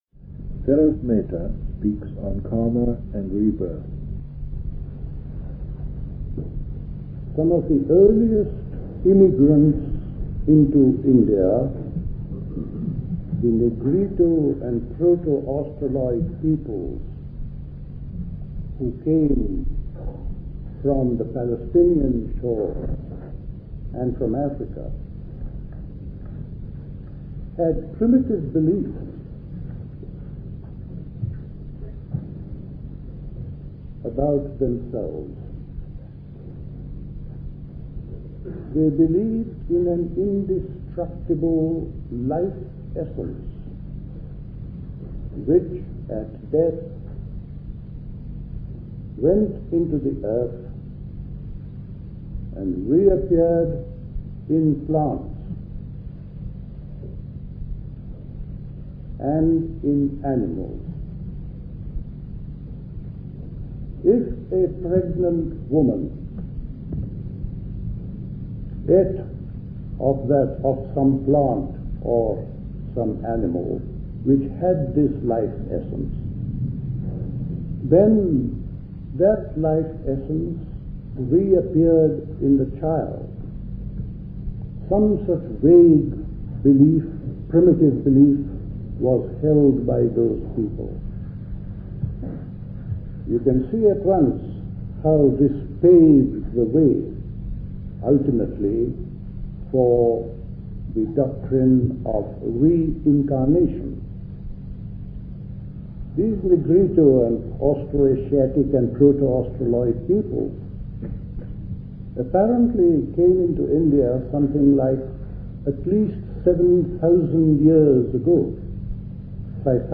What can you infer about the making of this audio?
Recorded at the 1975 Buddhist Summer School.